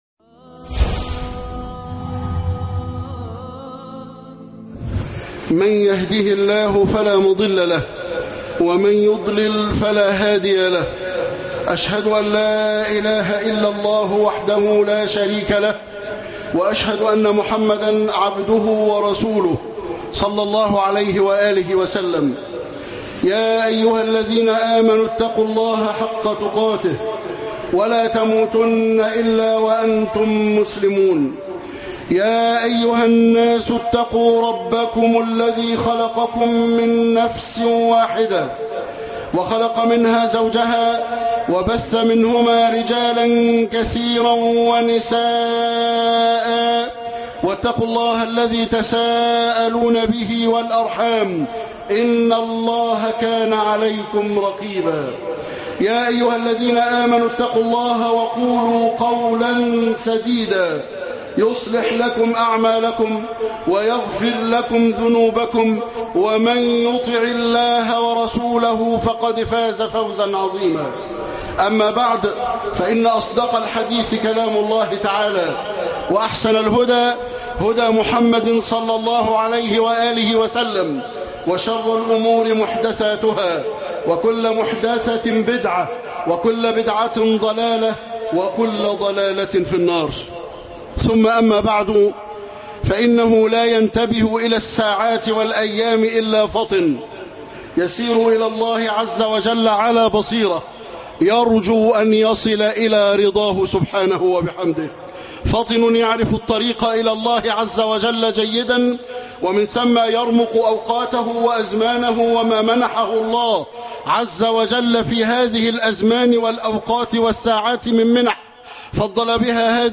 الدرس 16 تميم بن أوس الدارى